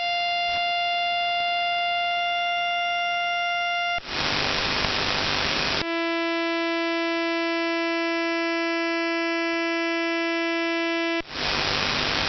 File:Ghadir 333-695Hz rates sample.mp3 - Signal Identification Wiki
Ghadir rdr new mode, AMAmplitude Modulation